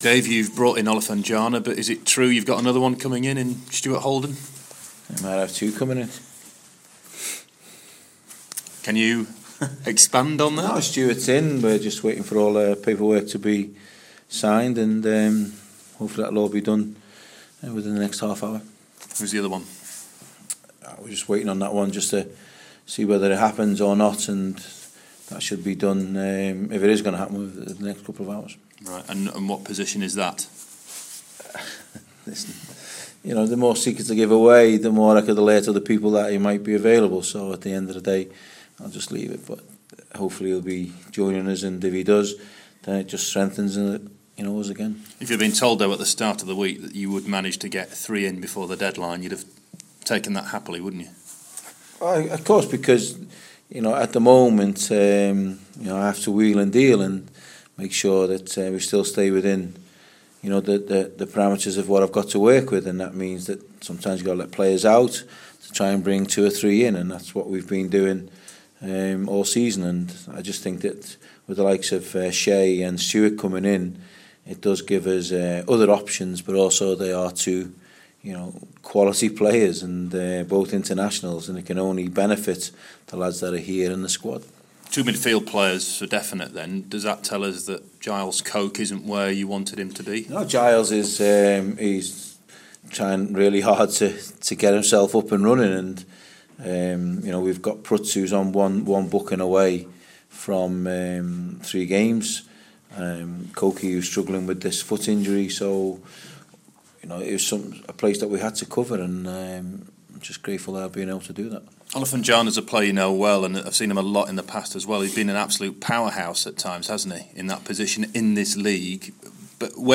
Sheff Wed manager Dave Jones latest interview pre Barnsley